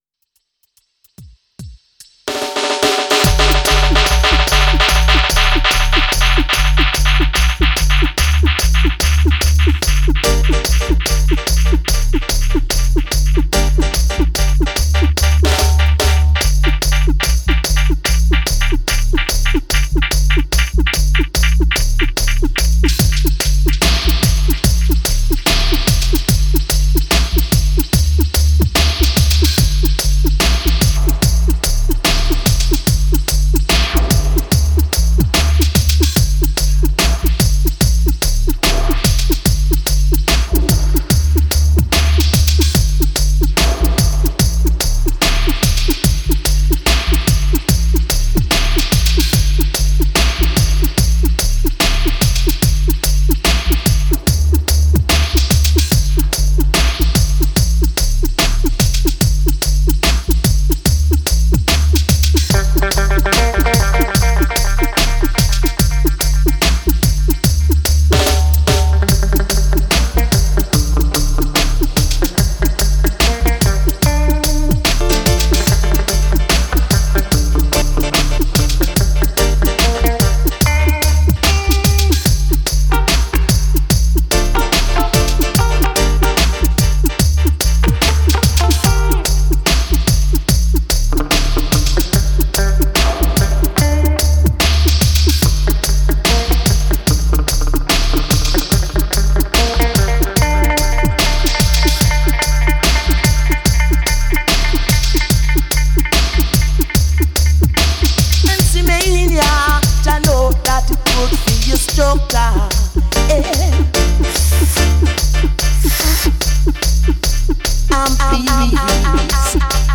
Genre: Reggae, Dub.